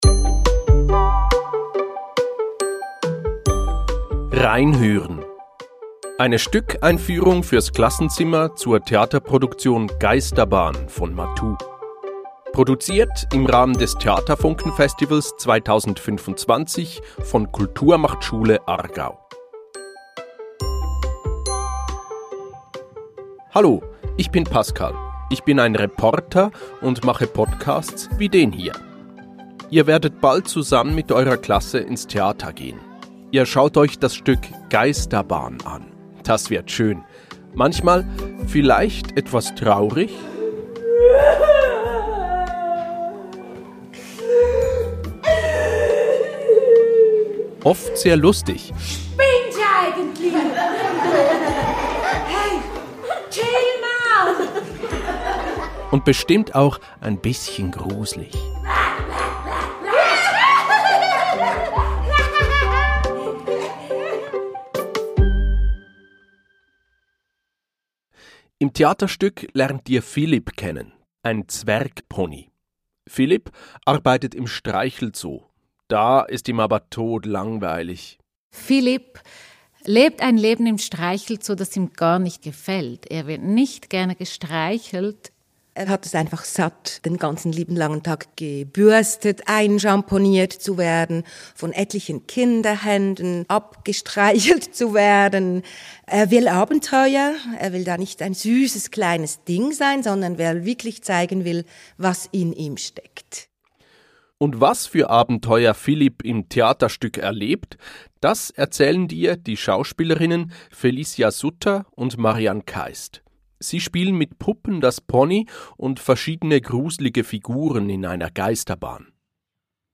Eine Stückeinführung für das Klassenzimmer.